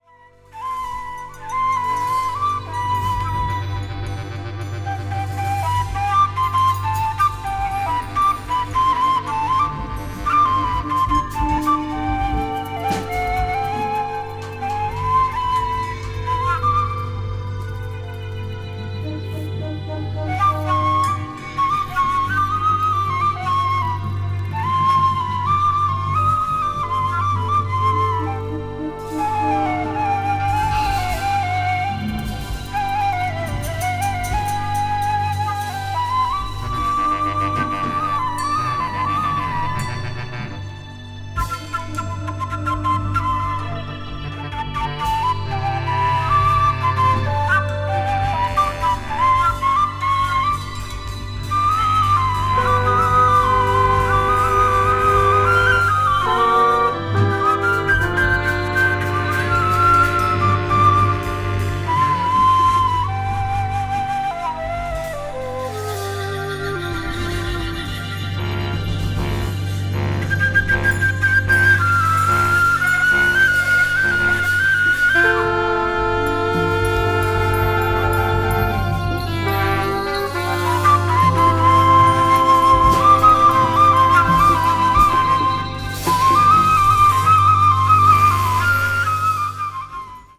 afro jazz   contemporary jazz   ethnic jazz   jazz orchestra